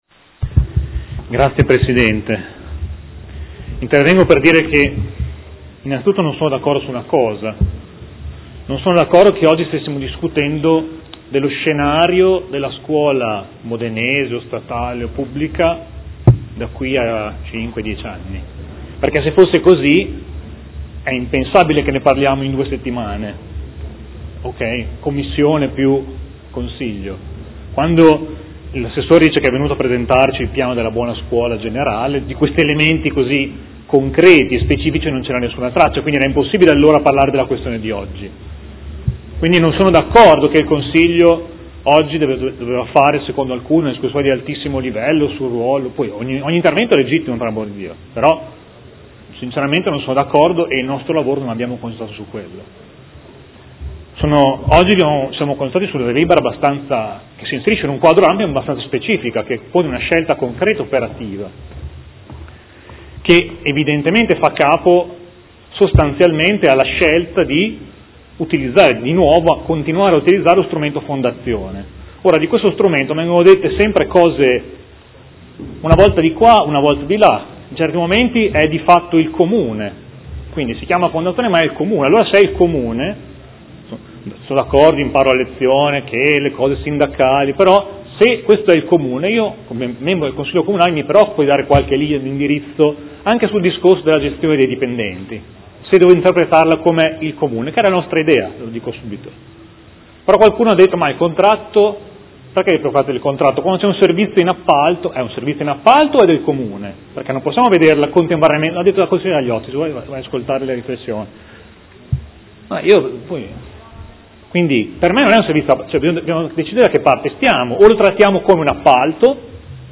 Seduta del 5/5/2016 Dichiarazione di voto.